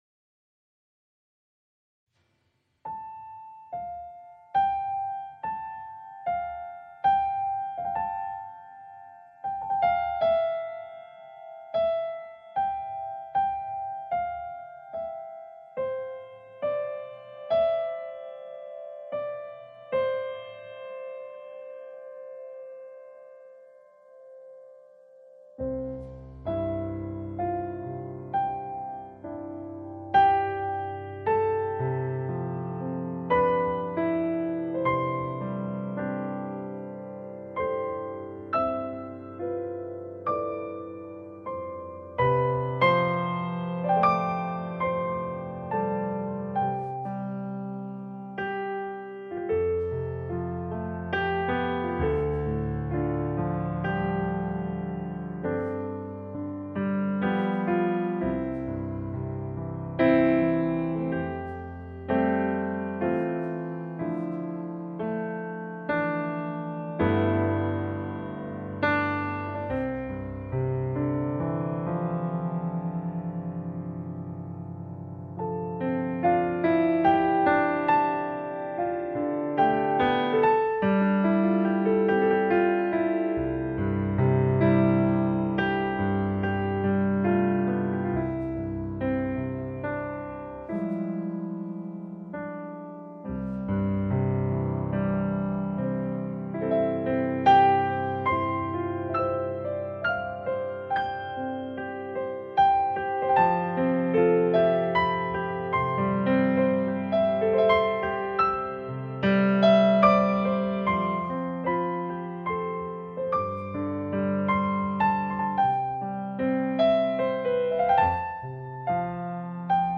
Piano landscapes meet electronic, jazz, balkans.